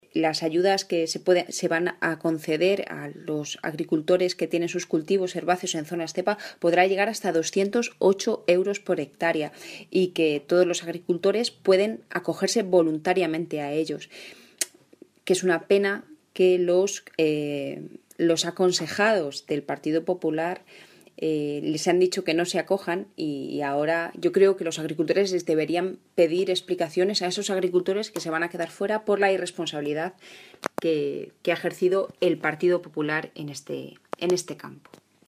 La diputada del Grupo Parlamentario Socialista en las Cortes de Castilla-La Mancha, Rosario García, ha mostrado su satisfacción por el presupuesto que este año la consejería de Agricultura va a destinar para los cultivos herbáceos en las zonas ZEPA.
Cortes de audio de la rueda de prensa